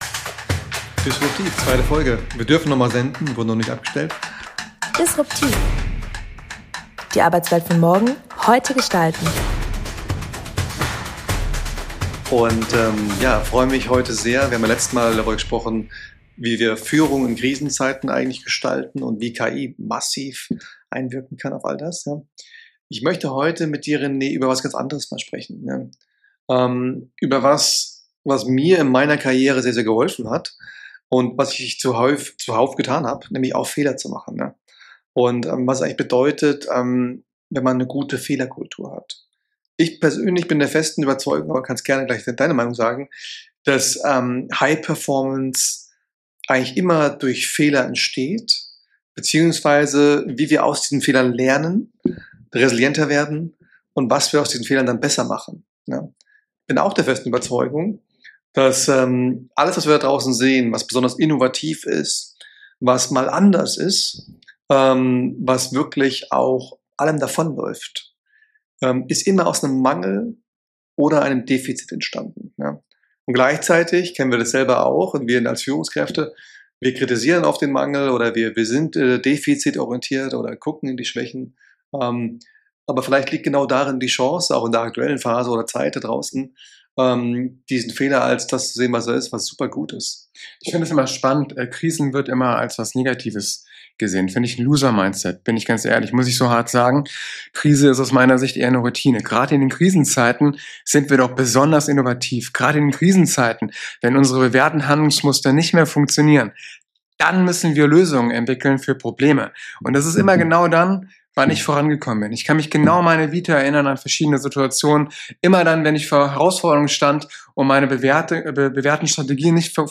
Es geht um Resilienz, Selbstreflexion, situatives Führen und die Kunst, „walk the talk“ wirklich zu leben. Ein ehrliches Gespräch darüber, wie Schmerz Wachstum erzeugt – und warum genau darin der Schlüssel zur Zukunft liegt.